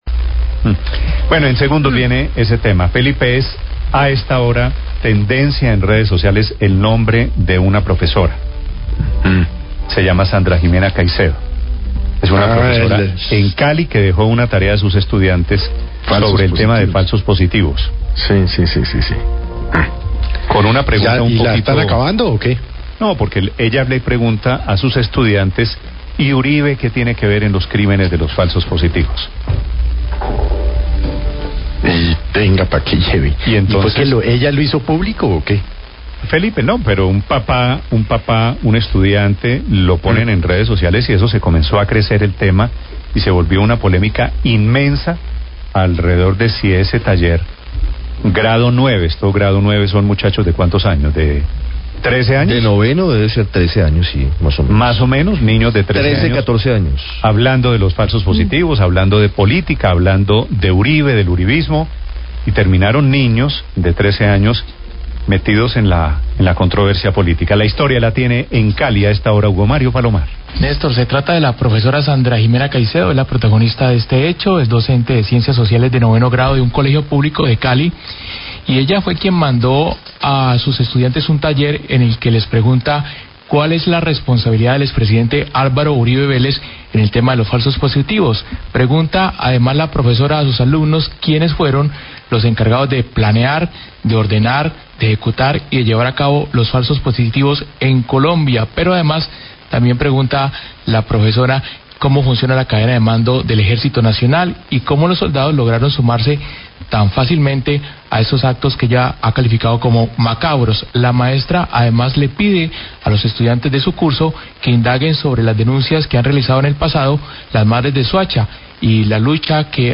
Radio
En una de las preguntas se pide que se indague sobre la responsabilidad del expresidente Álvaro Uribe en el tema de falsos positivos. Periodistas realizan un debate sobre el tema.